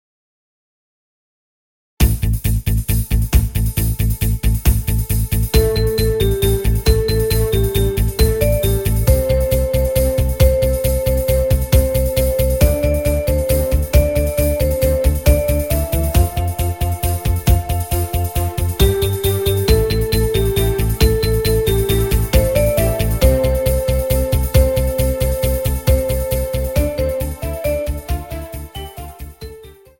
Instrumental Solos Viola